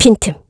Ripine-Vox_Skill1_kr.wav